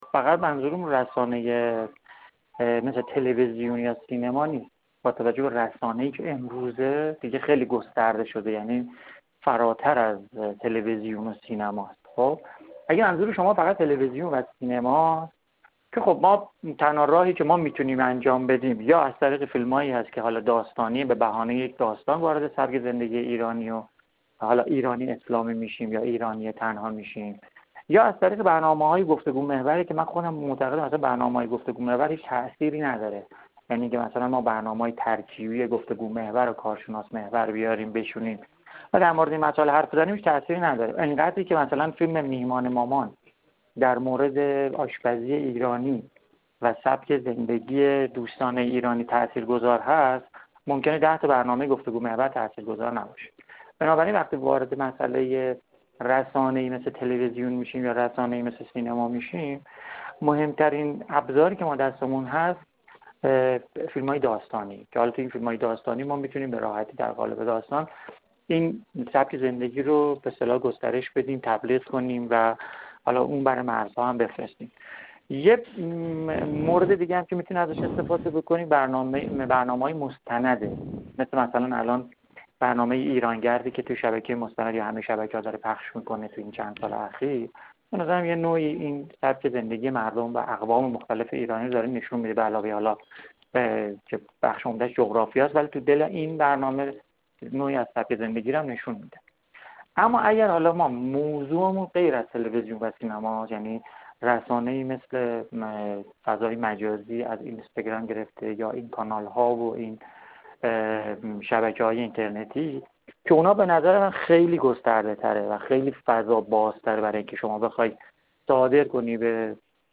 گفت‌و‌گویی